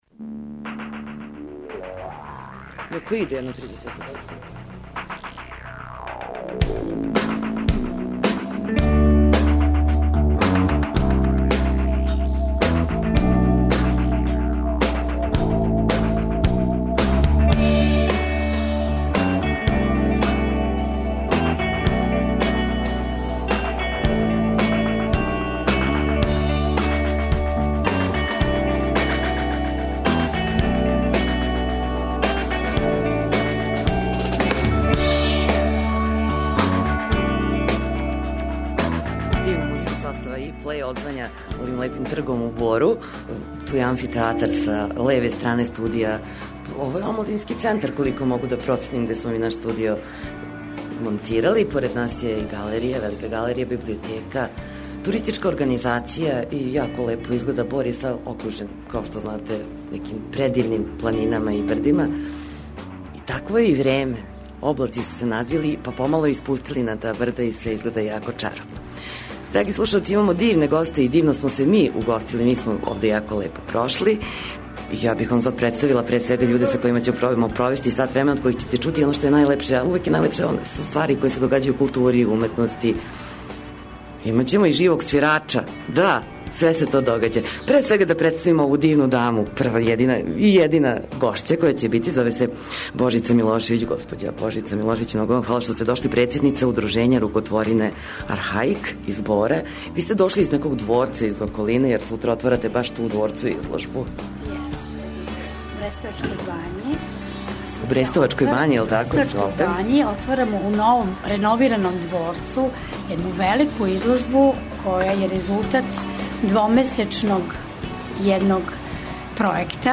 Емисија је емитована из Бора, имали смо занимљиве госте, свираче...